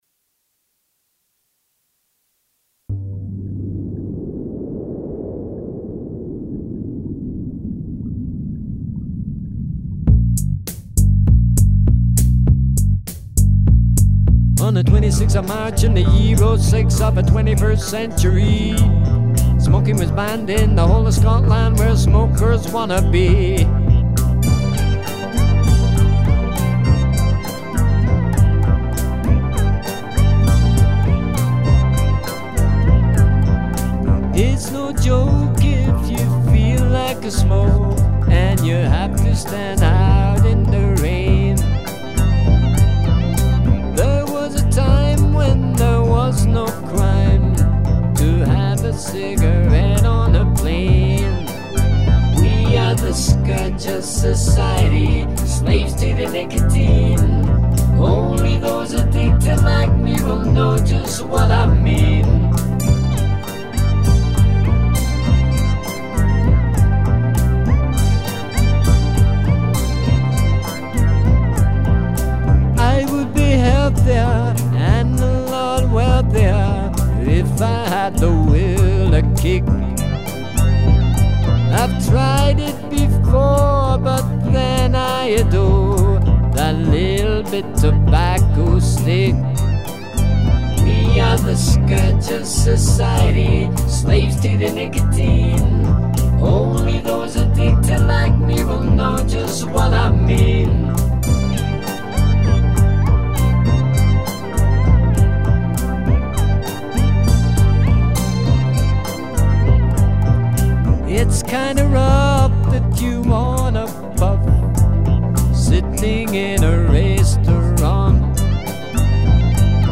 I record using cakewalk's Sonar5.
I use a Yamaha PSR-620, 1971 Fender Strat and Boss GTR-6 Multi effects processor.